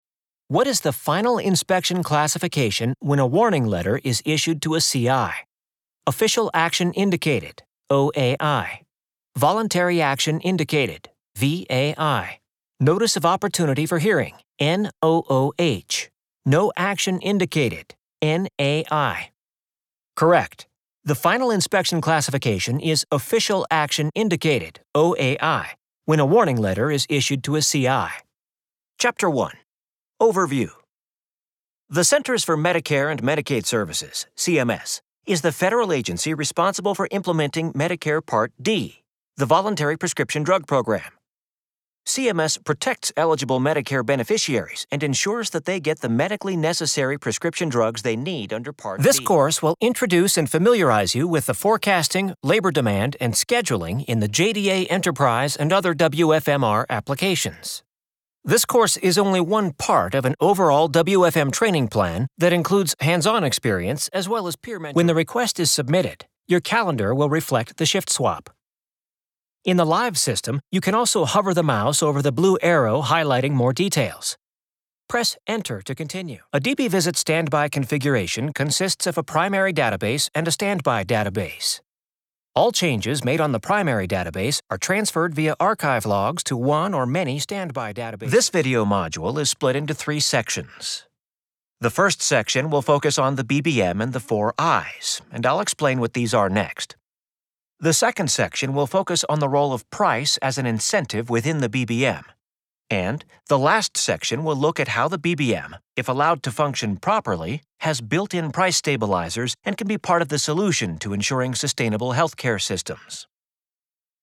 Warm and friendly.
Familiar and conversational.
His middle-aged voice falls into the mid range and upper range.
Training and eLearning
Neumann TLM103
eLearningDemo.mp3